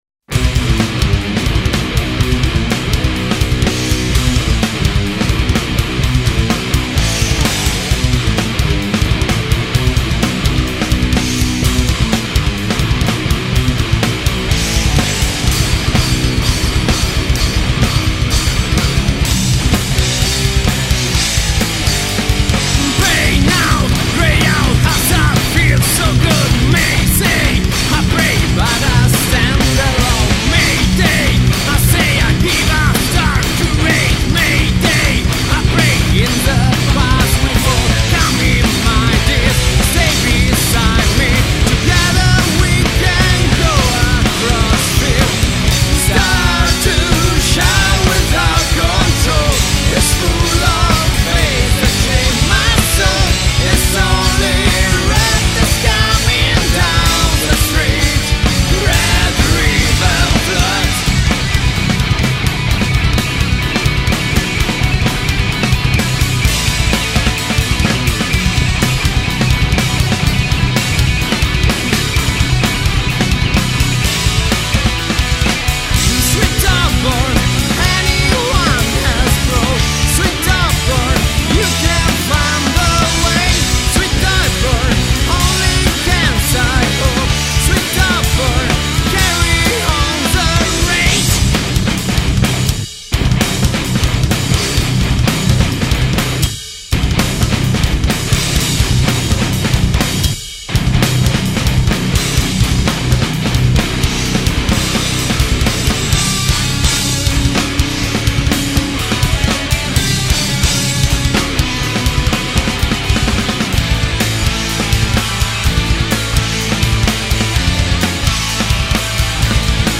GenereRock / Metal